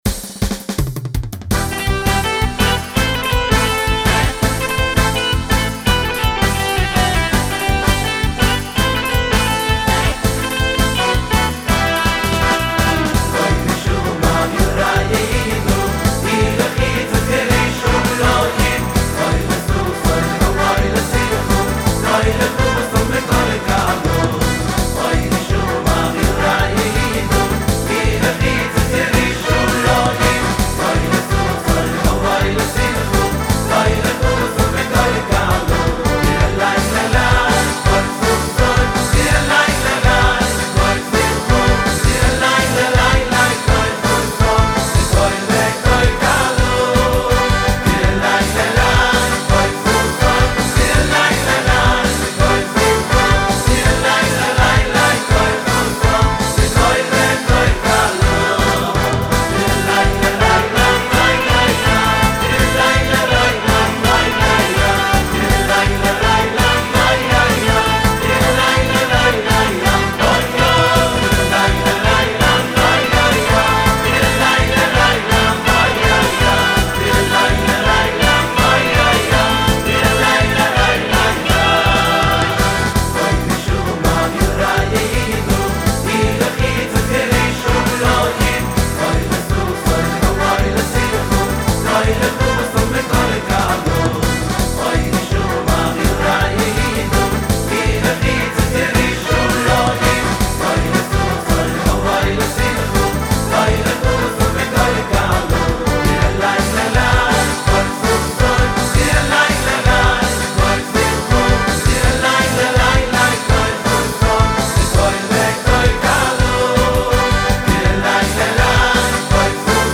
ניגון פצצה